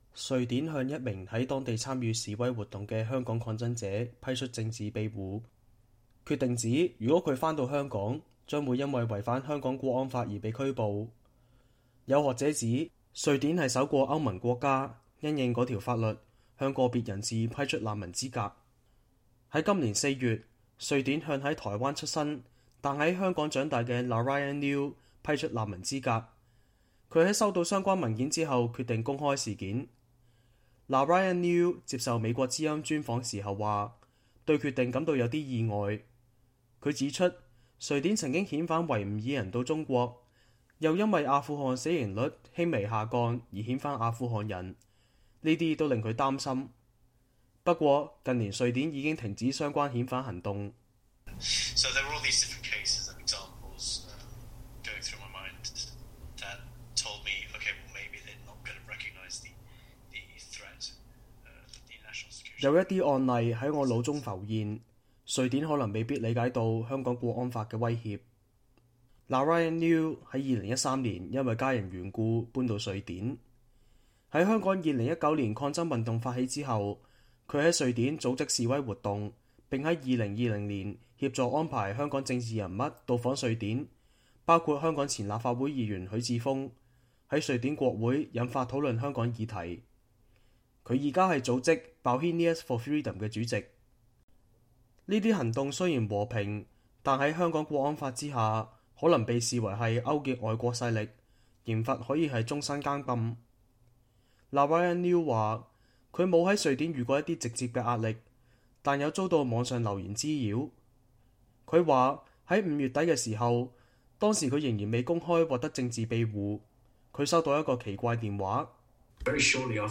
專訪：香港抗爭者於瑞典獲政治庇護 寄望當地保護更多港人